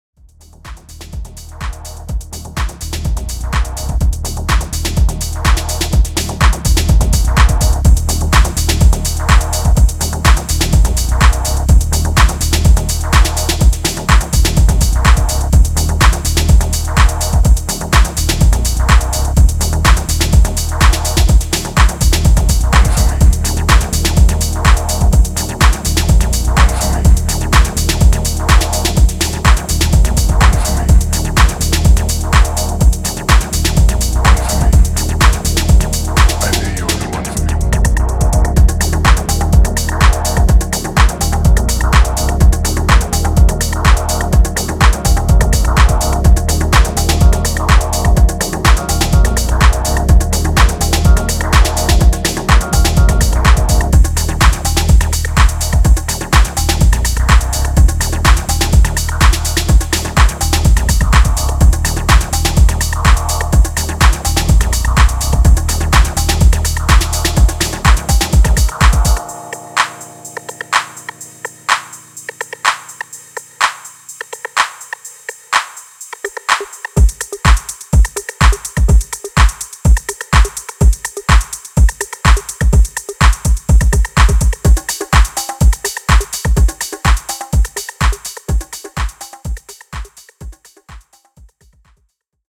ソリッドなディープ・ハウス群を展開しています。